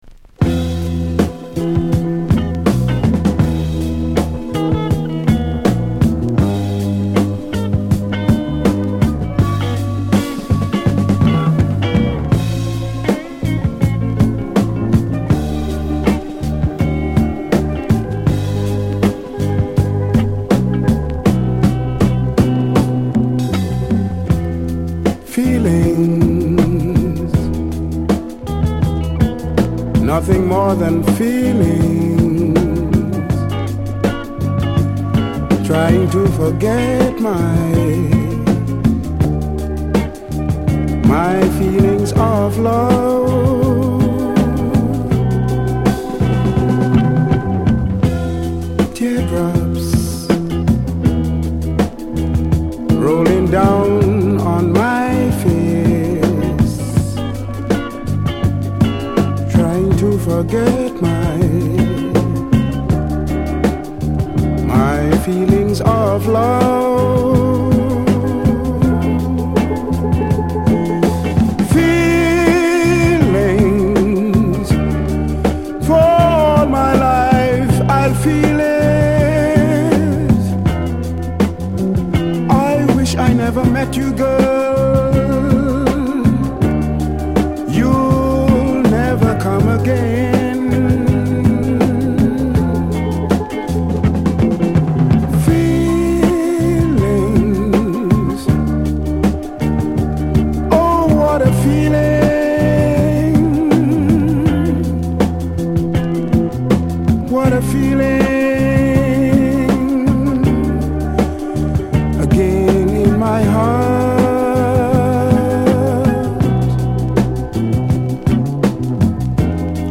その名の通り全編にわたりスティール・パンを効かせた、ダンスチューンがぎっしり詰まった一枚。